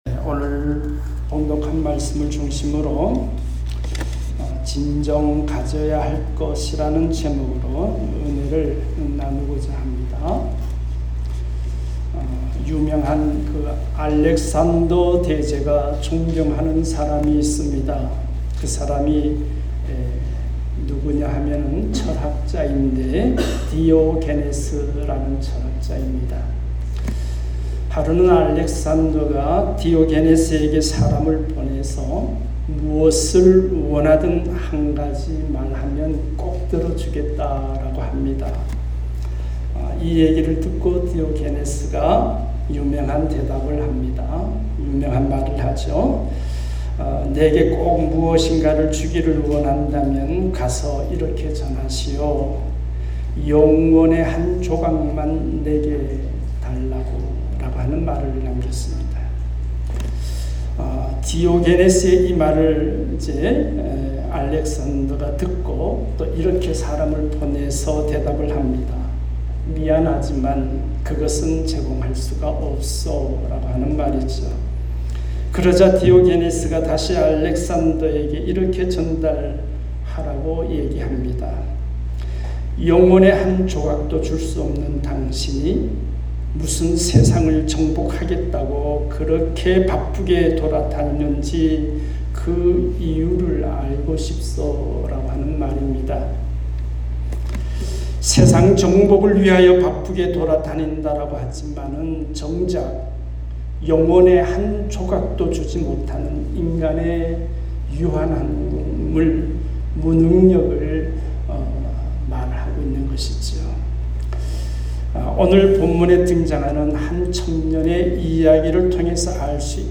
진정 가져야 할 것 ( 18:18-27 ) 말씀